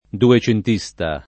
vai all'elenco alfabetico delle voci ingrandisci il carattere 100% rimpicciolisci il carattere stampa invia tramite posta elettronica codividi su Facebook dugentista [ du J ent &S ta ] o duecentista [ due © ent &S ta ] (lett. ducentista [ du © ent &S ta ]; tosc. duegentista [ due J ent &S ta ]) s. m. e f.; pl. m. ‑sti